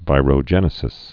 (vīrō-jĕnĭ-sĭs, -rə-)